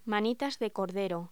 Locución: Manitas de cordero
voz